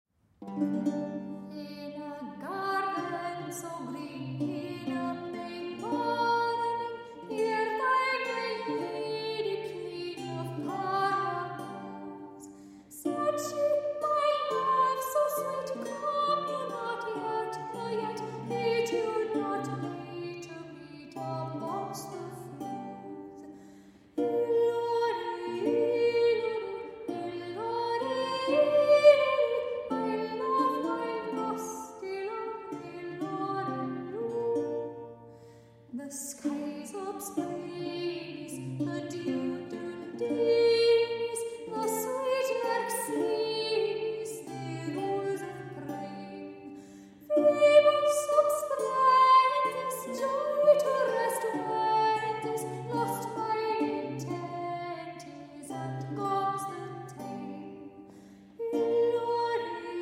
Soprano
Renaissance Lute